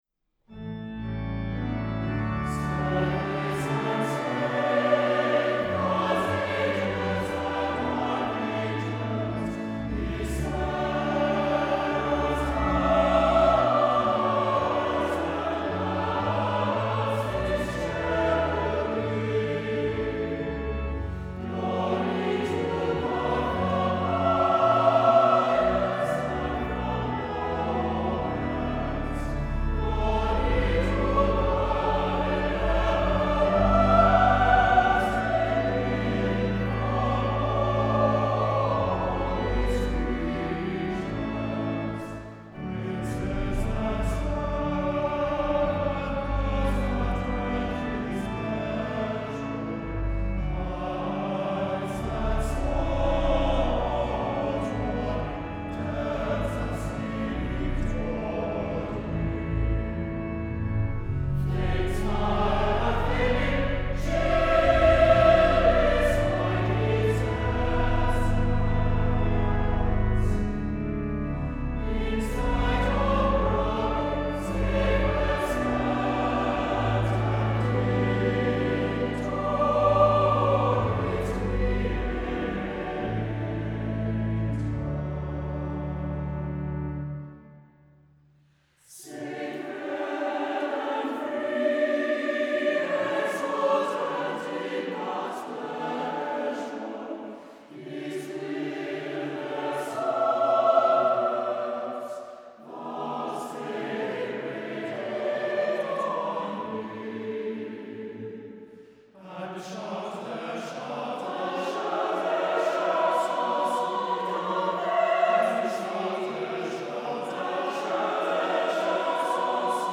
Voicing: SATB divisi and Organ